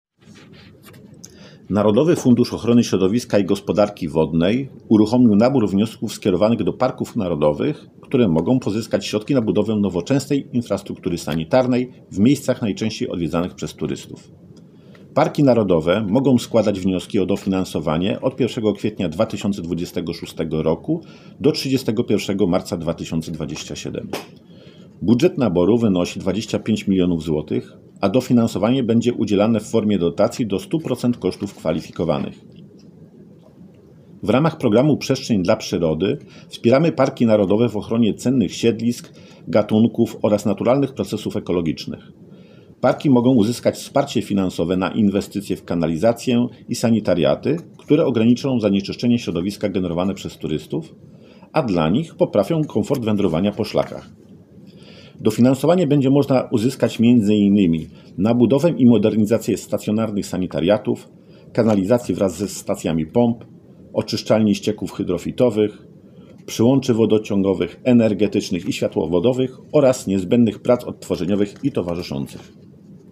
powiedział Robert Gajda, zastępca prezesa NFOŚiGW.
Robert Gajda - wypowiedź